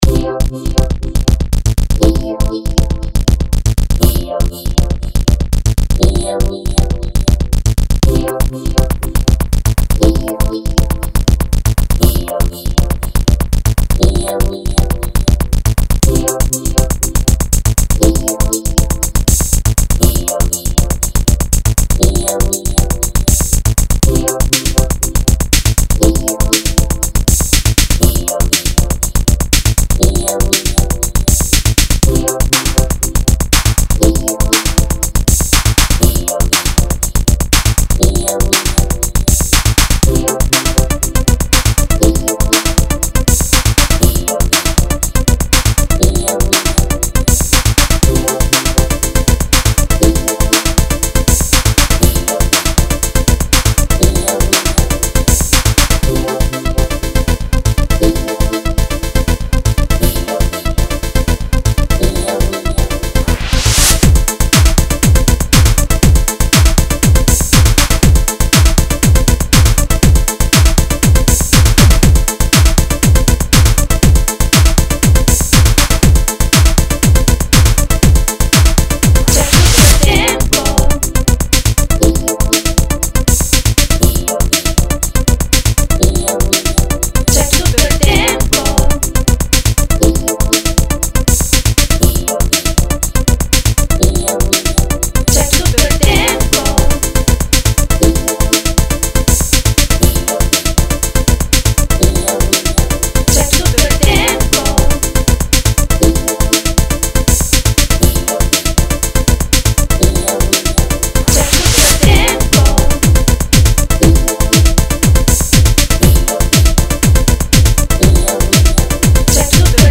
a colpi di filo spinato vocale
ambient